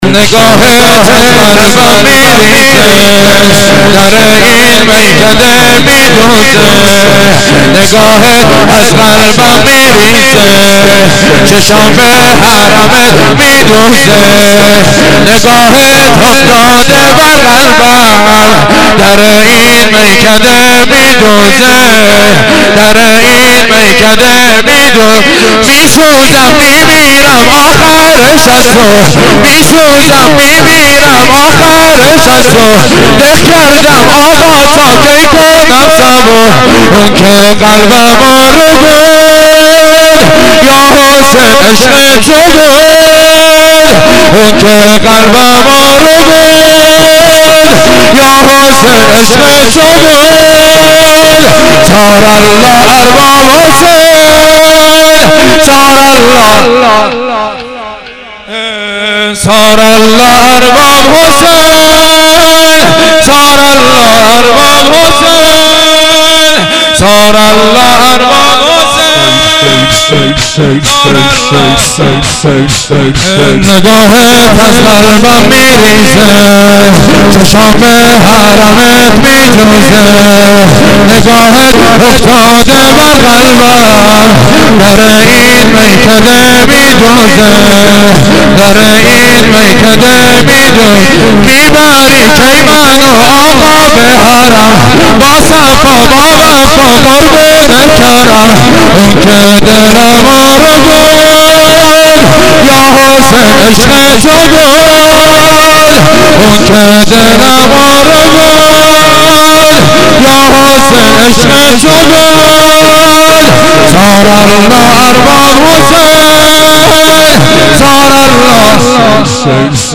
فایل های صوتی مراسم هفتگی مرداد 94
haftegi-22-mordad-94-shor2.mp3